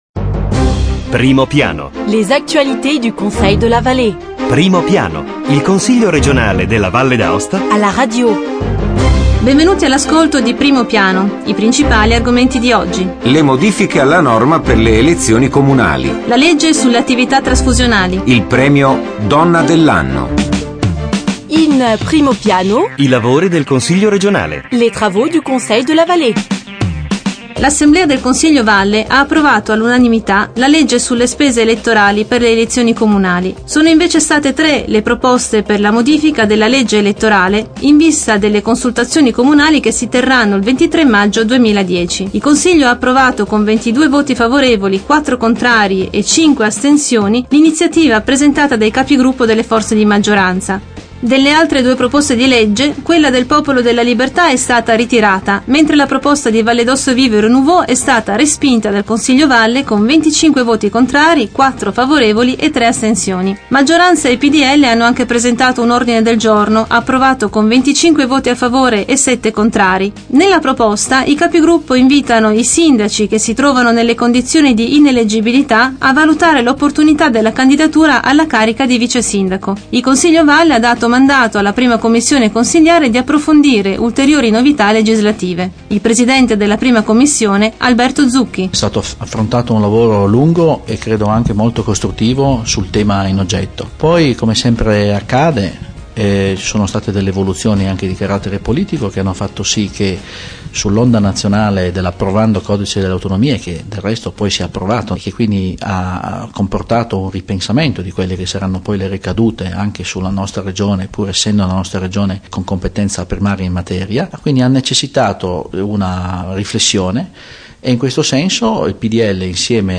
Evénements et anniversaires Documents liés De 24 novembre 2009 à 1er décembre 2009 Primo piano Le Conseil r�gional � la radio: approfondissement hebdomadaire sur l'activit� politique, institutionnelle et culturelle de l'assembl�e l�gislative.